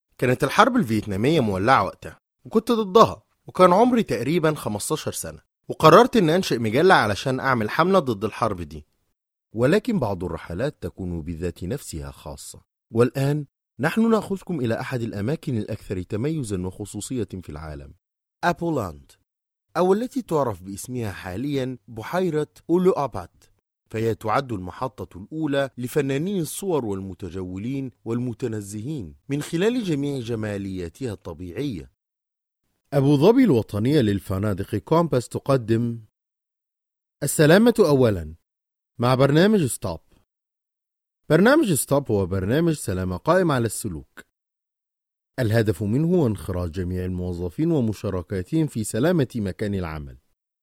Mısır Arapçası Seslendirme
Erkek Ses